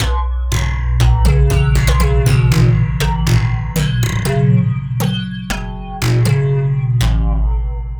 Borg Delay.wav